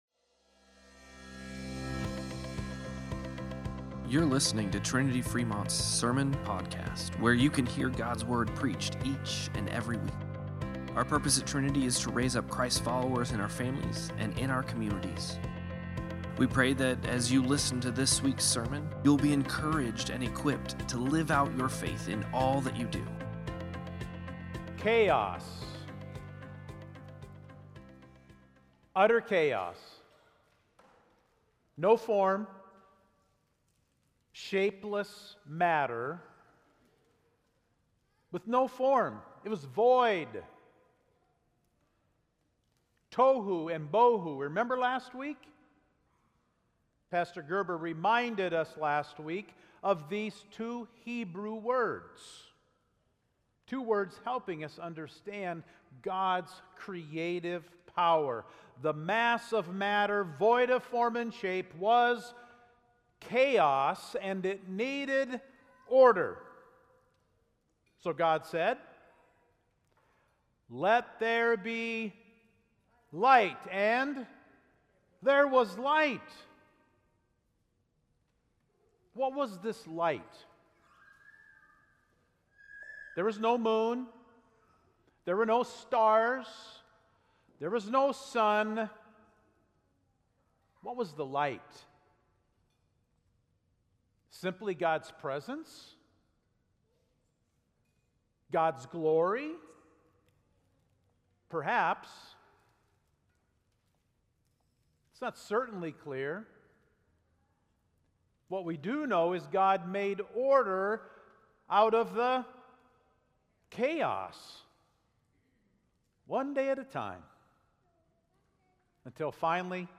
10-12-Sermon-Podcast.mp3